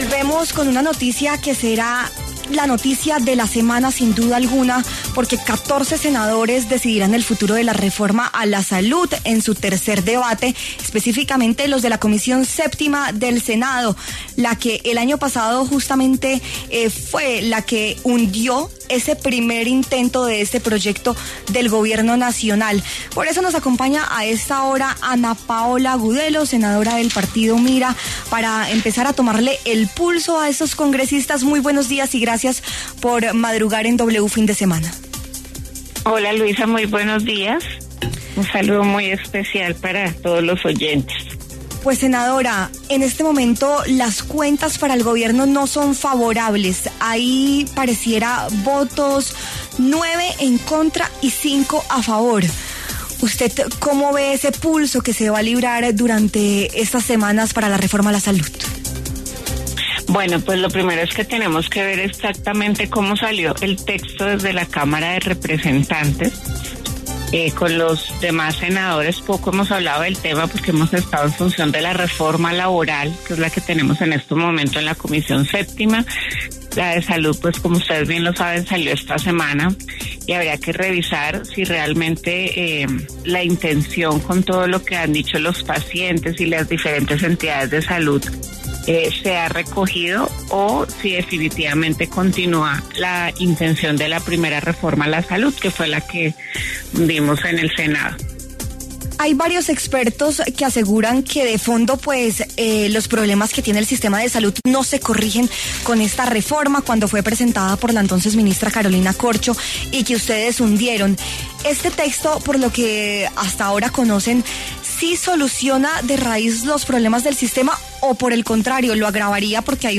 Sin embargo, para hablar sobre qué le espera a este articulado en esa sección del Senado, por los micrófonos de W Fin De Semana habló Ana Paola Agudelo, senadora del Partido Mira.